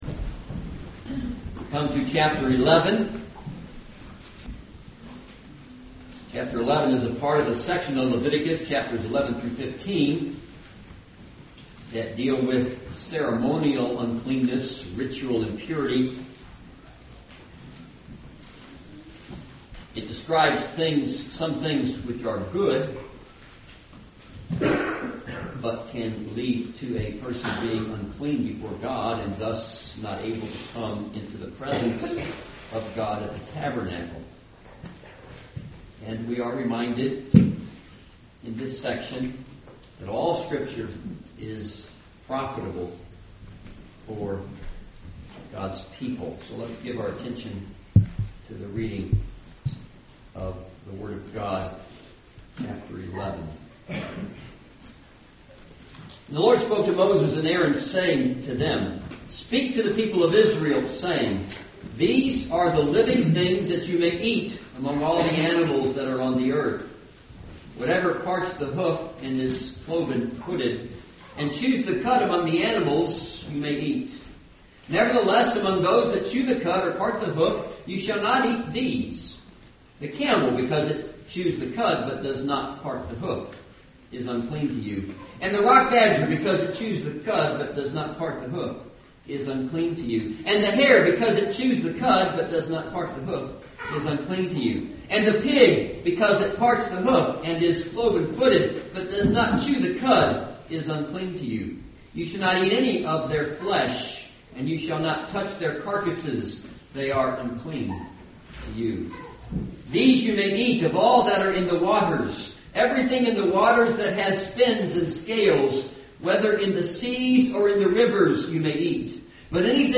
This is a sermon on Leviticus 11.